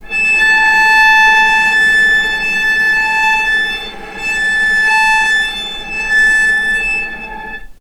vc_sp-A5-mf.AIF